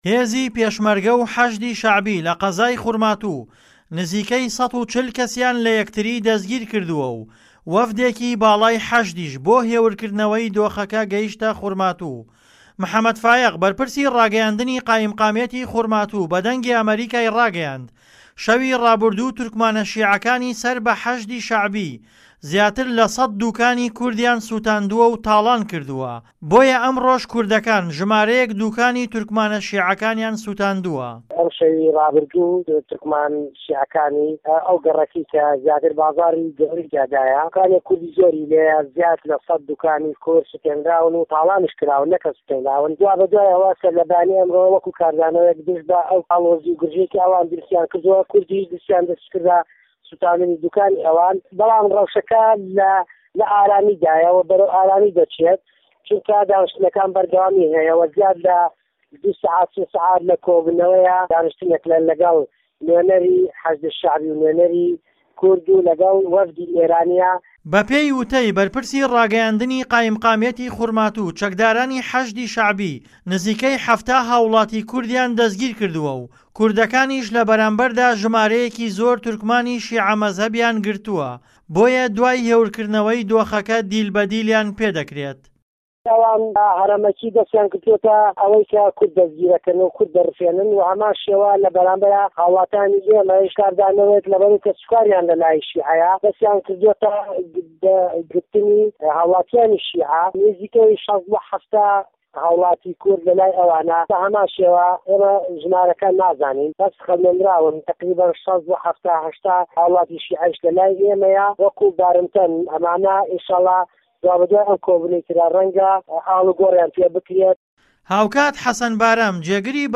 ڕاپـۆرتی